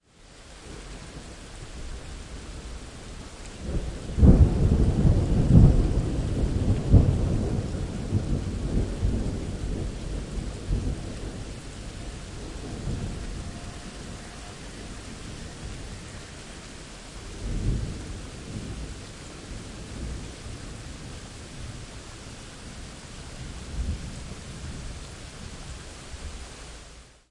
风暴 " temporale3
描述：只是一些雷鸣的记录
标签： 闪电 性质 天气
声道立体声